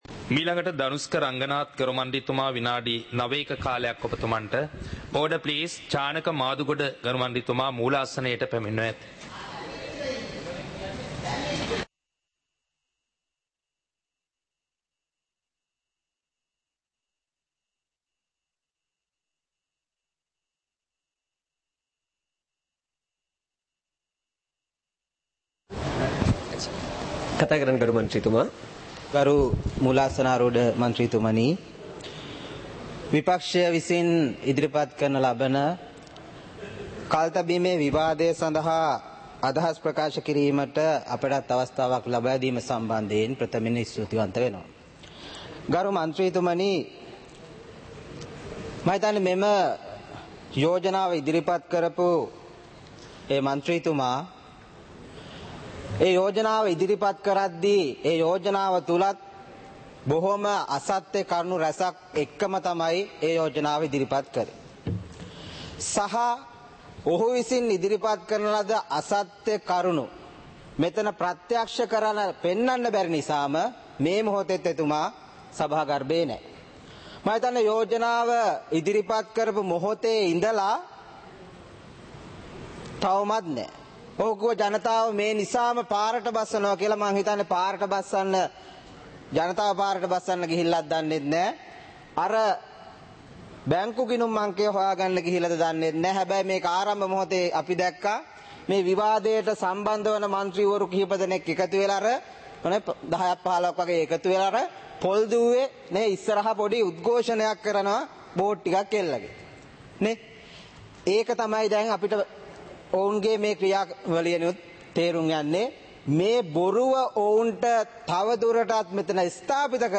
සභාවේ වැඩ කටයුතු (2026-02-20)
පාර්ලිමේන්තුව සජීවීව - පටිගත කළ